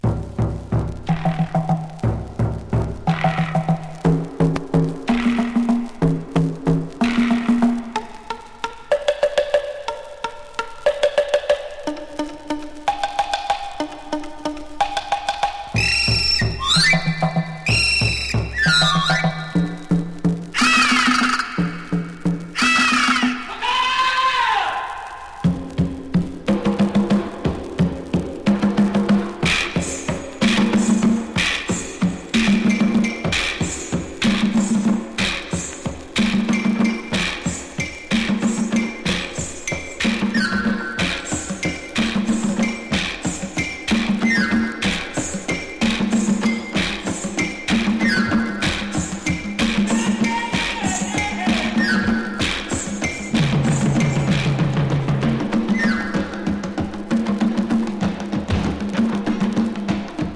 アフロ・エキゾ盤。